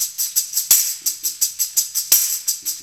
Tambo_Loop_A_170.wav